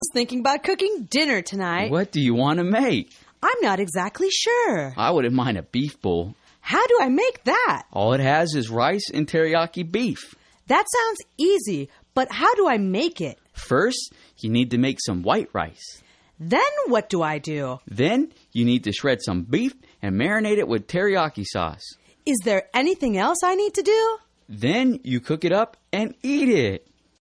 英语情景对话：How to Cook a Meal(1) 听力文件下载—在线英语听力室